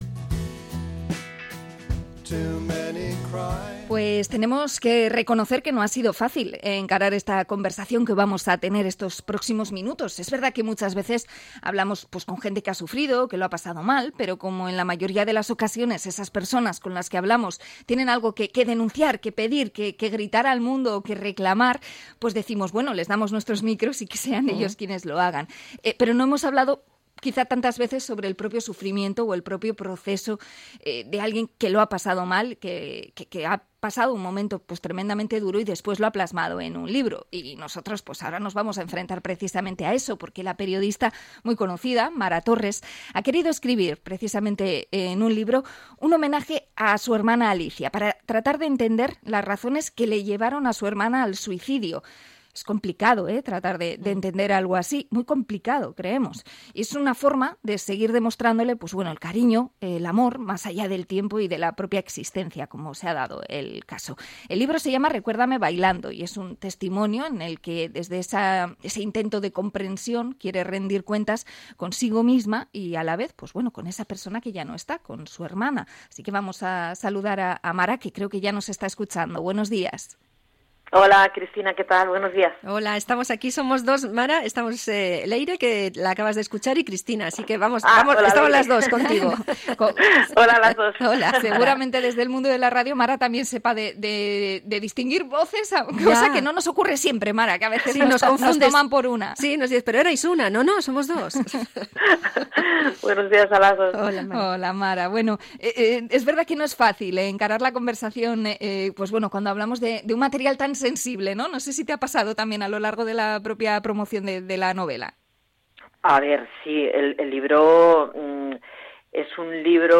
Entrevista a Mara Torres por su libro